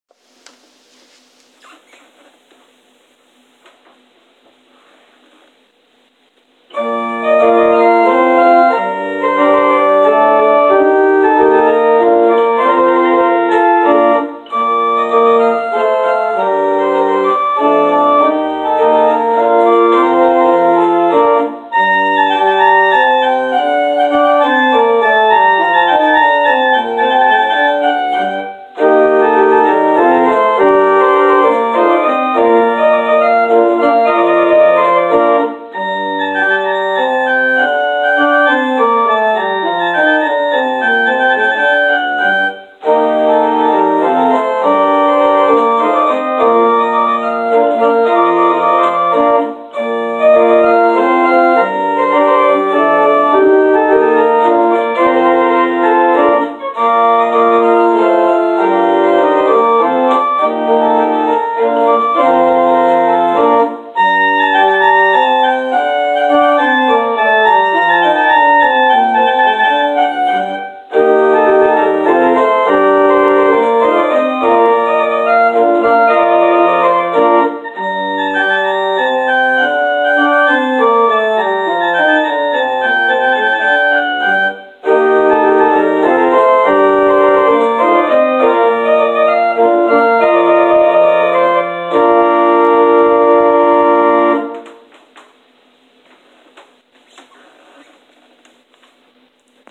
Postlude: “Hornpipe” – Henry Purcell